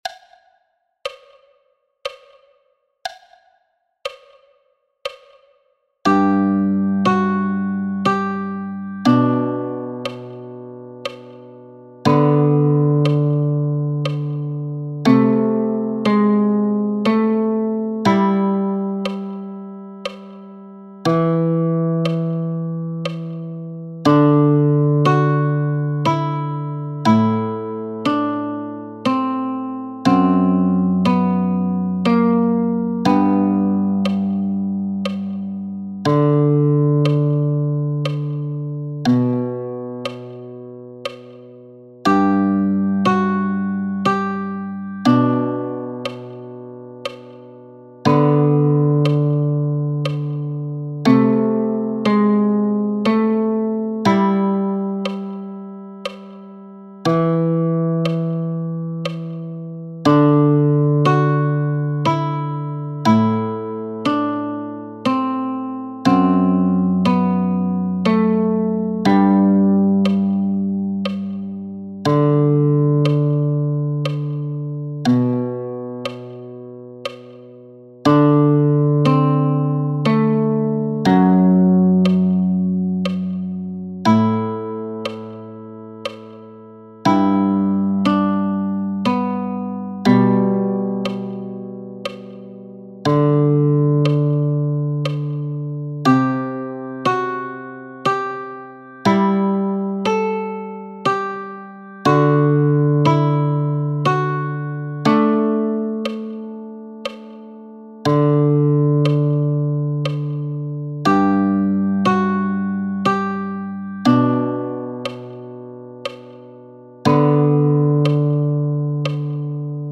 Der Song ist in einem langsamen Tempo aufgenommen. Die Instrumentierung wurde so gewählt dass sich der Klang möglichst wenig mit dem des eigenen Instrumentes vermischt.